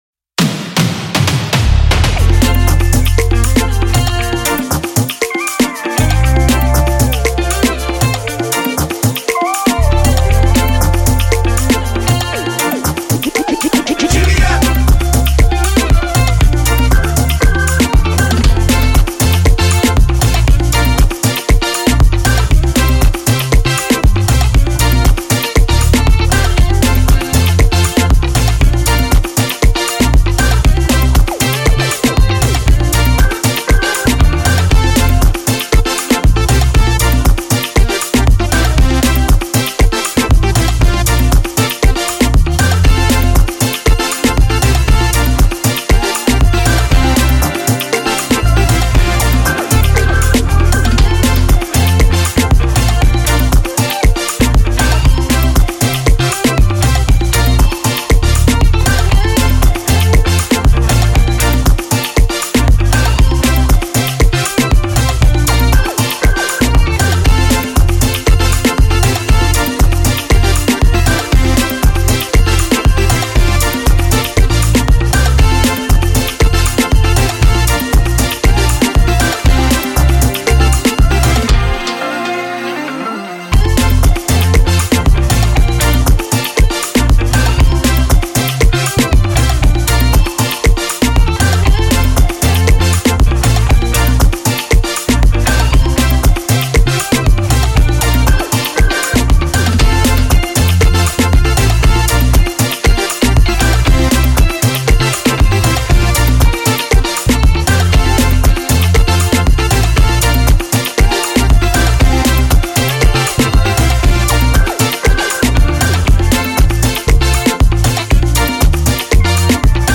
Riddim Instrumental